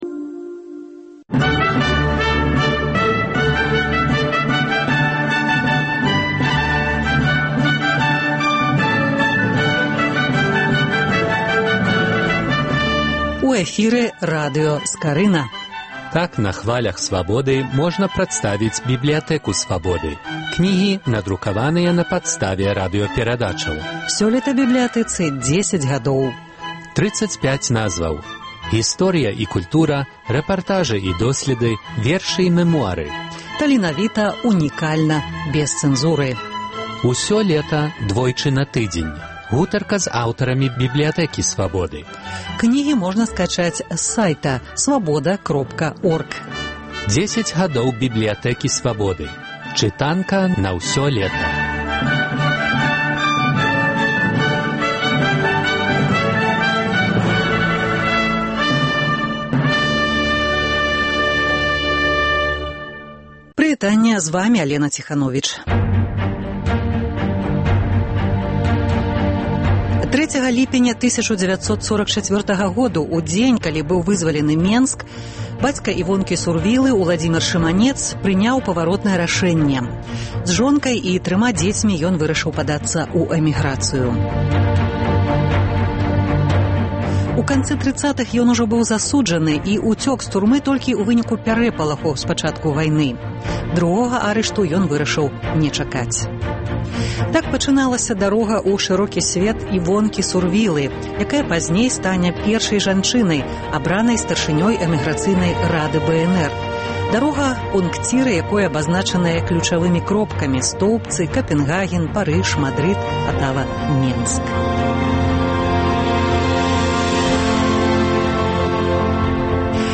Працяг радыёсэрыі “10 гадоў “Бібліятэкі Свабоды”. Гутарка са старшынёй Рады БНР пра кнігу яе ўспамінаў "Дарога".